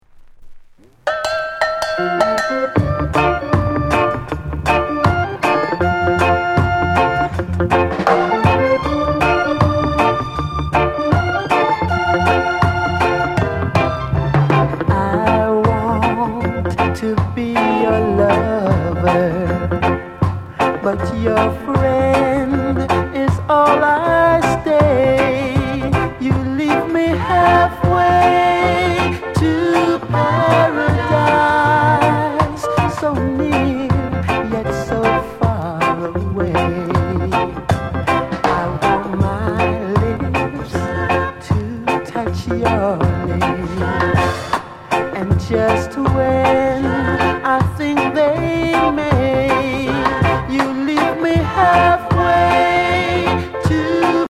NICE VOCAL ROCKSTEADY